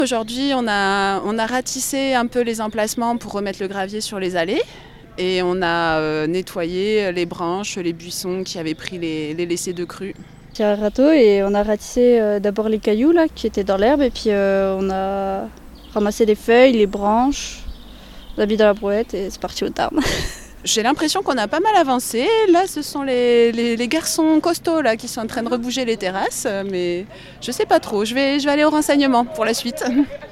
Bénévoles